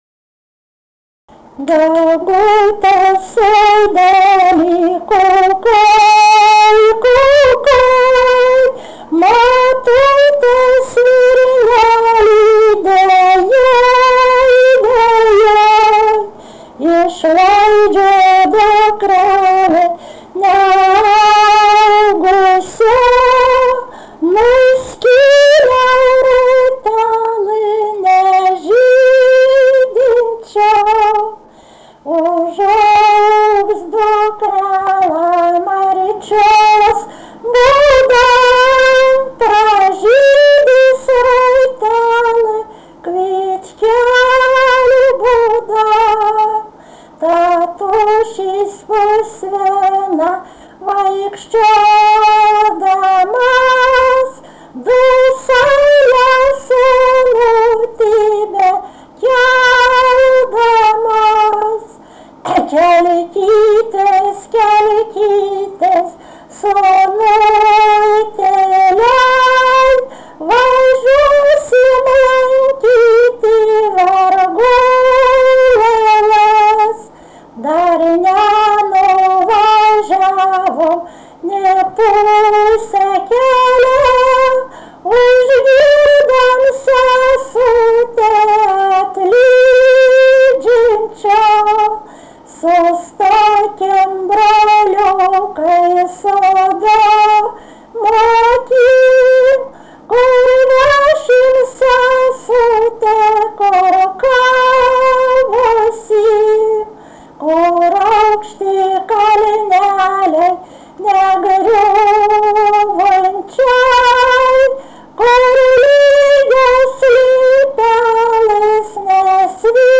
Dalykas, tema daina
Erdvinė aprėptis Dirvonai
Atlikimo pubūdis vokalinis